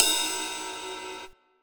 80s Digital Cymbal 02.wav